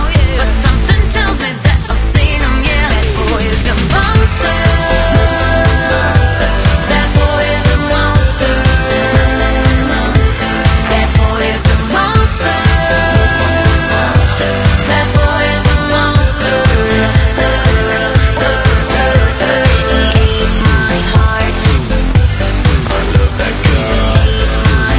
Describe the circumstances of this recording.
Reduced quality: Yes It is of a lower quality than the original recording.